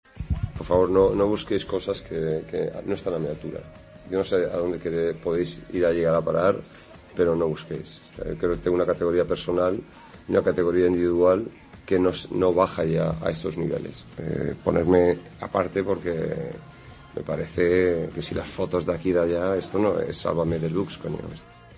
Ante la insistencia de las preguntas en la sala de prensa, el entrenador ha traslado las cuestiones a la directiva y ha sido tajante.